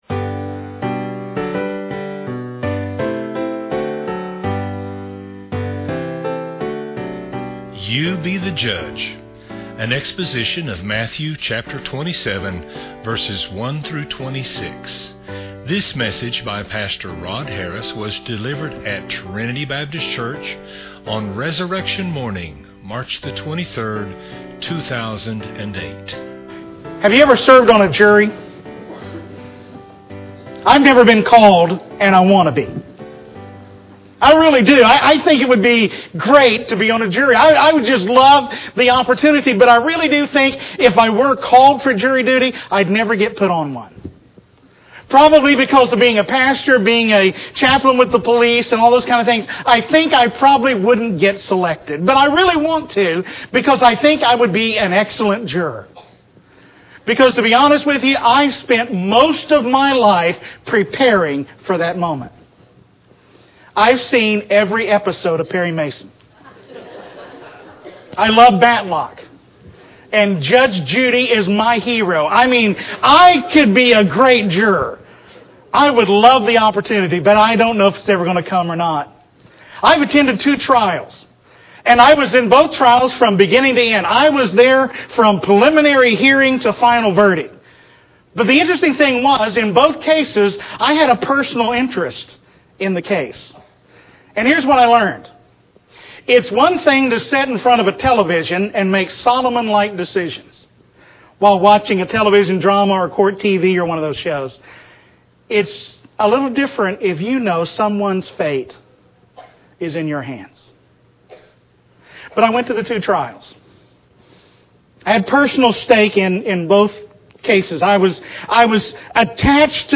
Resurrection Morning, 2007